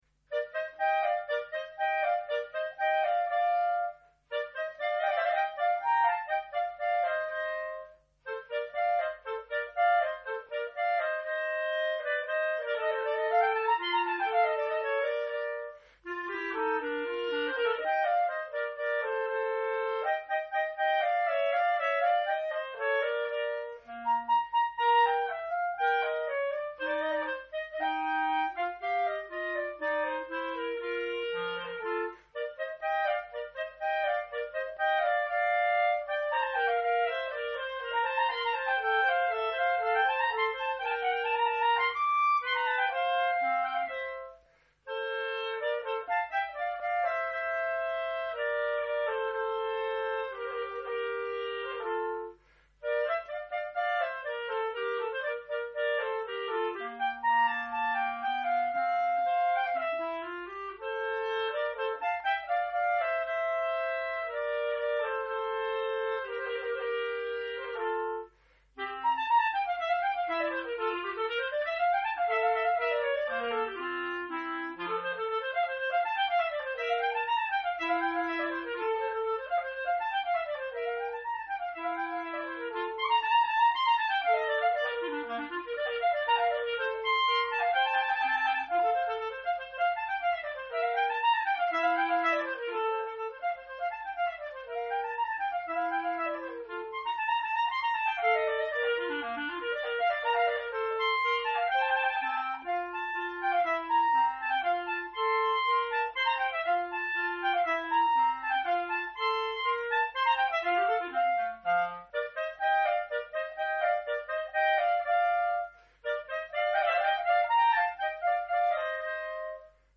Duo N° 1 opus 5 en ut M.
1e mouvement, Allegro (6'06)